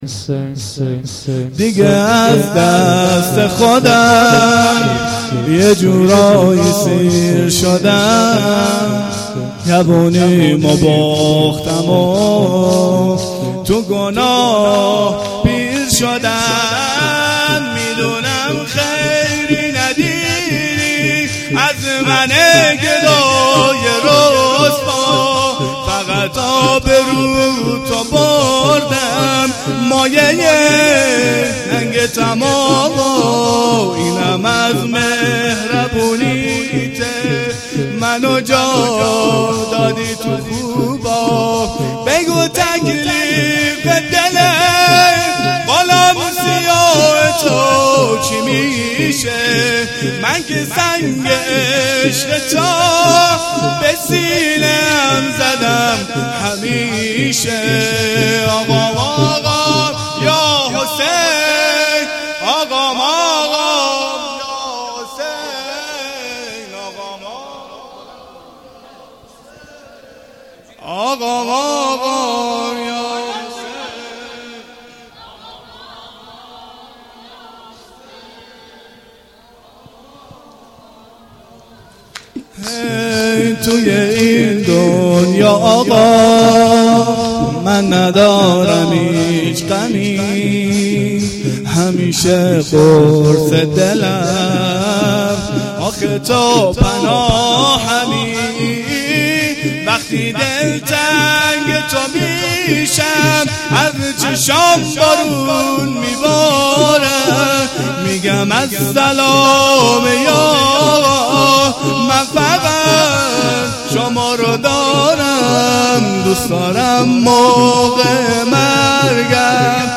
گزارش صوتی جلسه هفتگی2دیماه
شور1